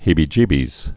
(hēbē-jēbēz)